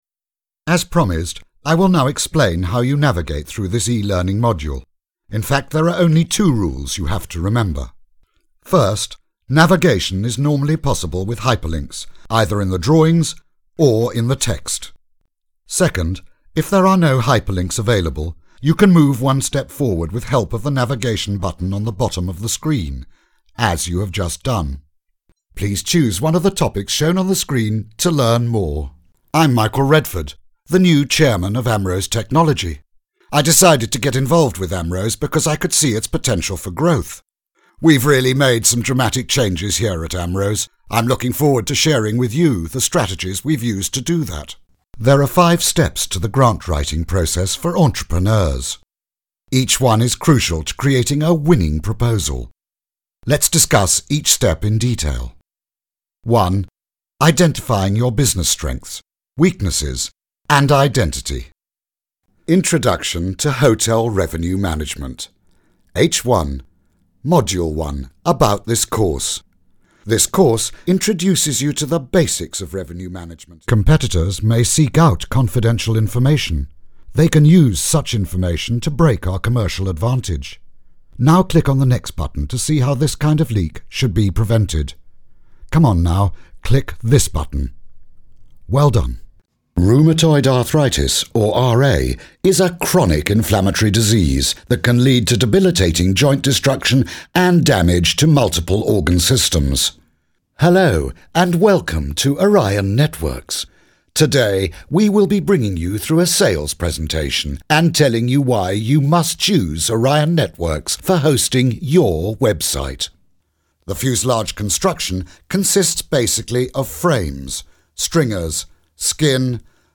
He was given an education that formed his classic English accent - and a voice, deep and warm, that records perfectly and gives that extra edge to any production.
englisch (uk)
Sprechprobe: eLearning (Muttersprache):
A true British voice, warm, friendly & very English.